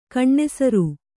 ♪ kaṇṇesaru